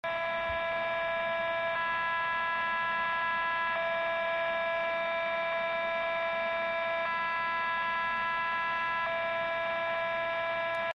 noise.mp3